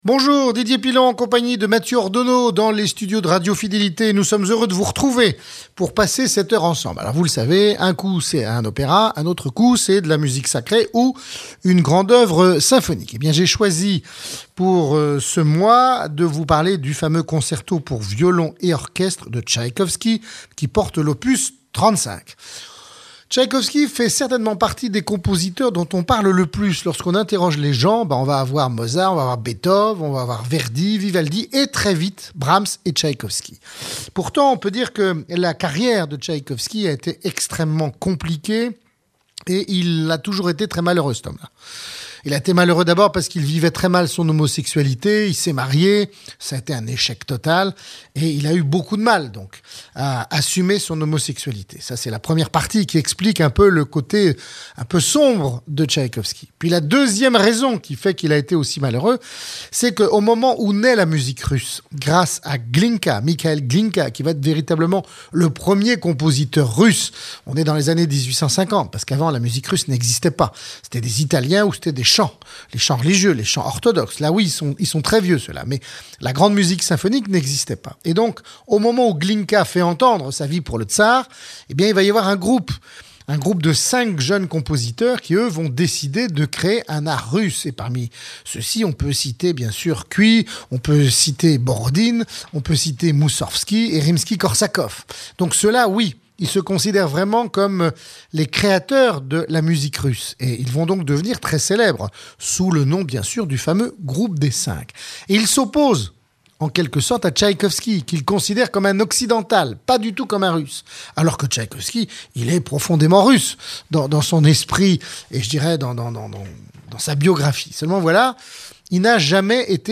DP-Tchaikoski - Concerto pour violon en ré majeur et Marche slave